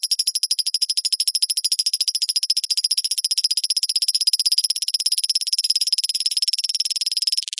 描述：有点像卡通片中的踮脚尖的声音，（下载后听起来要好很多）。